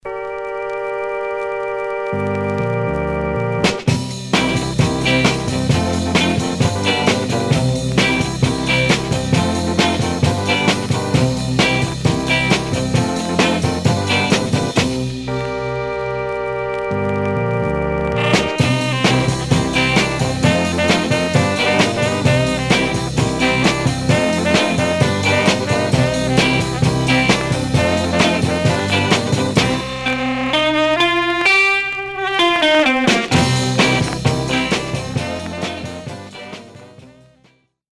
Surface noise/wear
Mono
R & R Instrumental